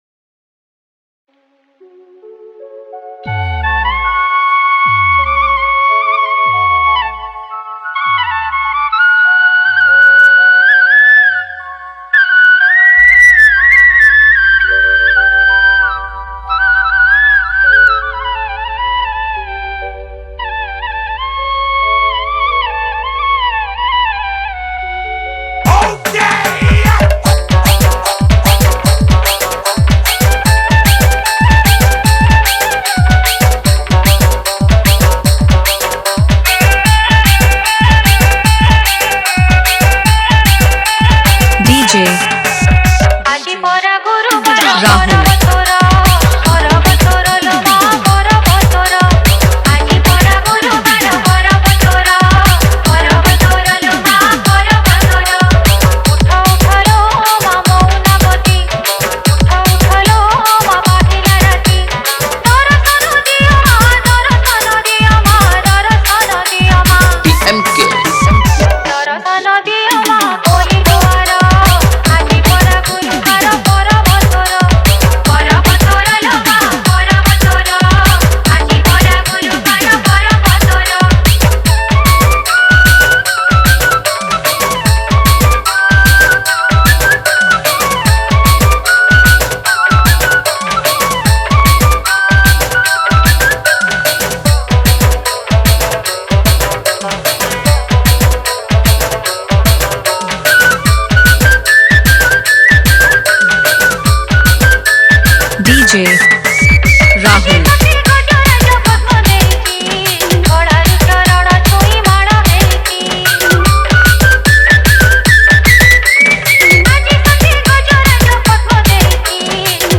Category:  Odia Bhajan Dj 2024